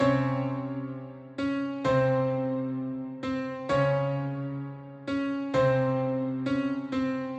描述：简单的三角钢琴
Tag: 130 bpm Trap Loops Piano Loops 1.24 MB wav Key : C